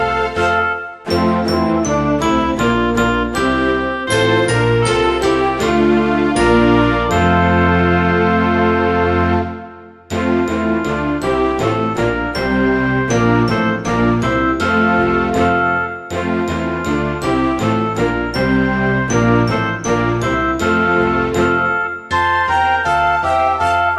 Irish Backing Tracks for St Patrick's Day